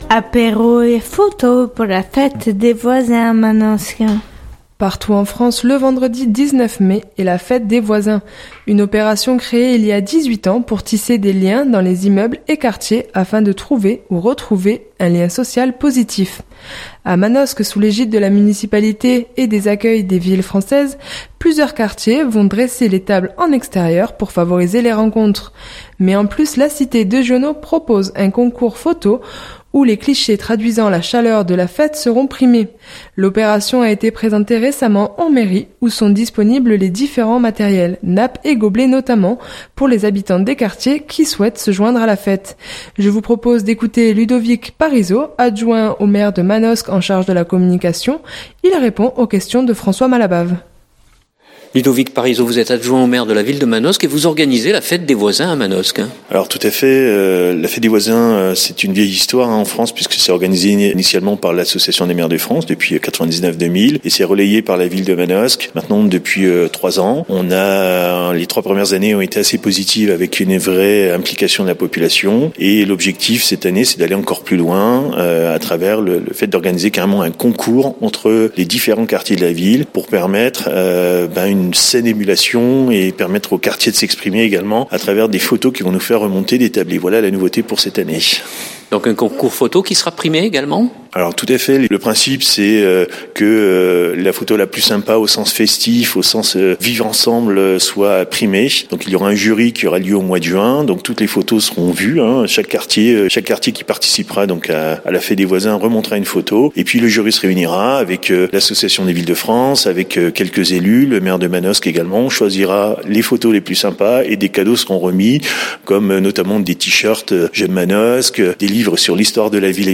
Je vous propose d’écouter Ludovic Parisot, adjoint au maire de Manosque en charge de la communication.